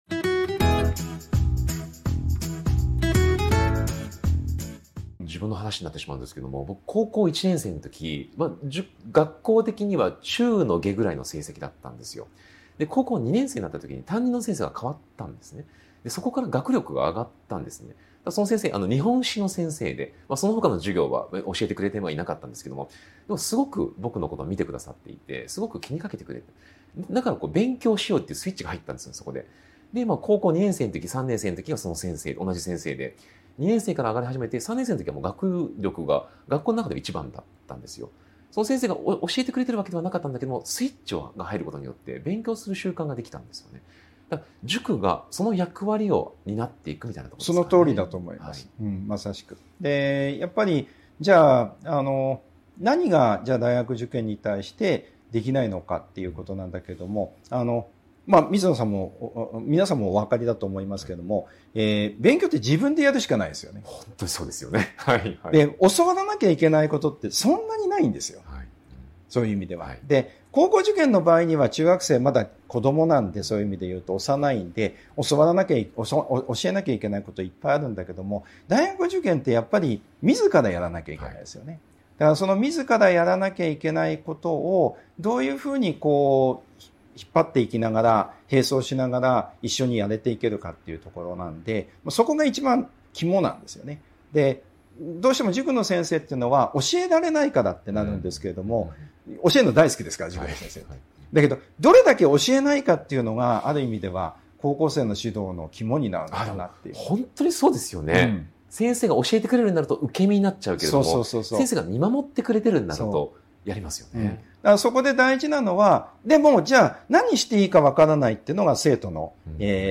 【特別インタビュー】塾業界38年の現場から見える未来の学習塾経営とは 個人塾が生き残るための「縦展開」と「寄り